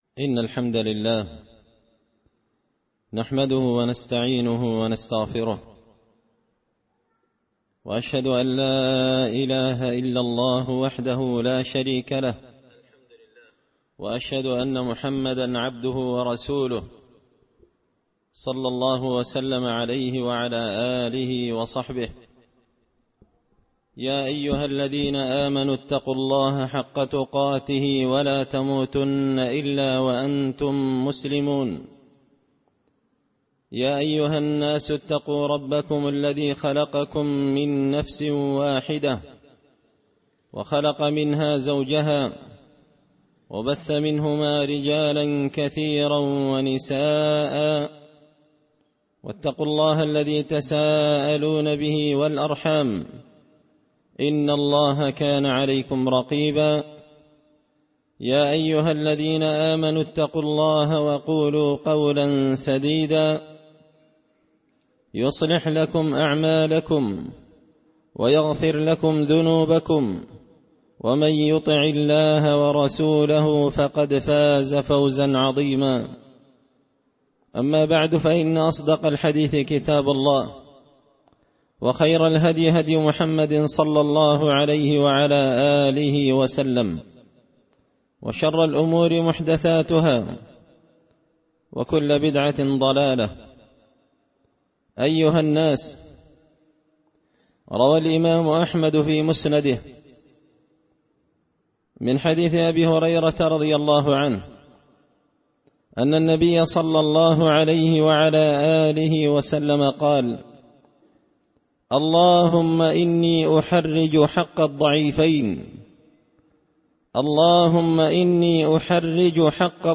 خطبة جمعة بعنوان – حق المرأة
دار الحديث بمسجد الفرقان ـ قشن ـ المهرة ـ اليمن